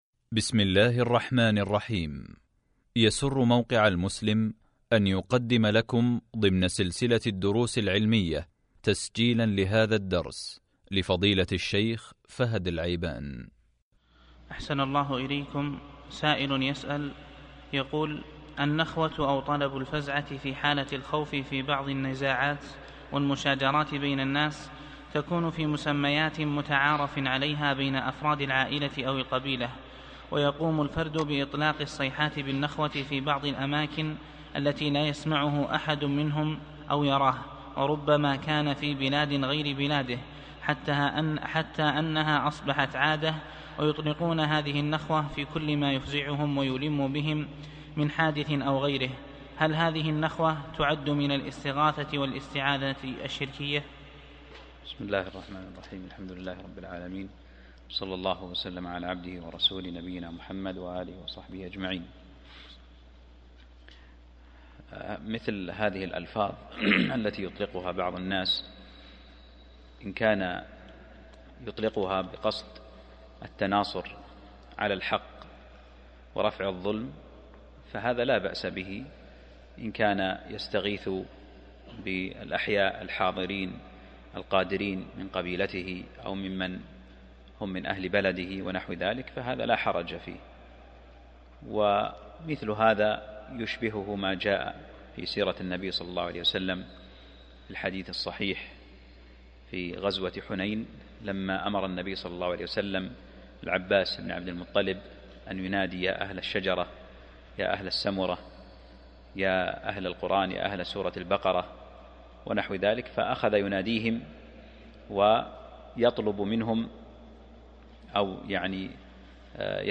الدرس (40) من شرح كتاب التوحيد | موقع المسلم